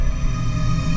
LOCOMOTI.WAV